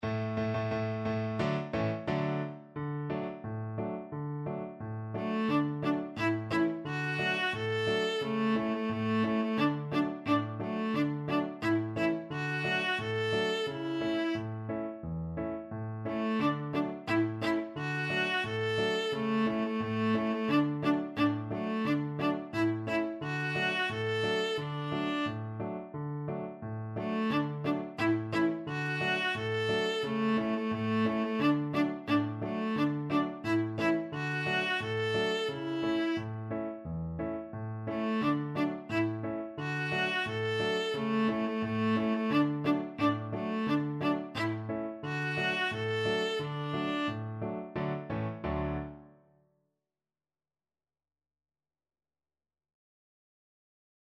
Viola
Traditional Music of unknown author.
D major (Sounding Pitch) (View more D major Music for Viola )
Steady march =c.88